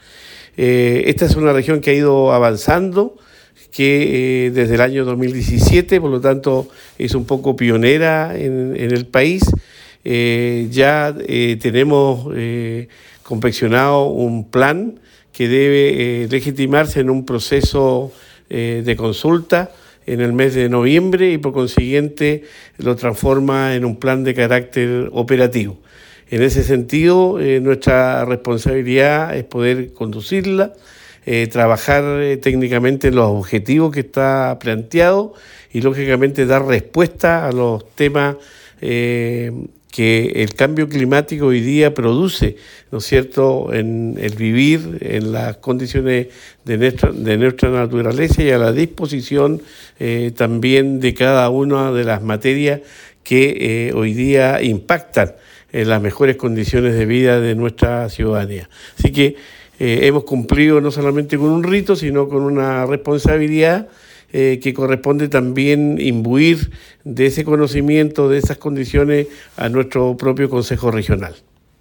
• Durante la sesión plenaria del Consejo Regional de Los Ríos, la autoridad dio a conocer los avances de la ley de cambio climático en la región.
Gobernador-Cuvertino-CORECC-1.mp3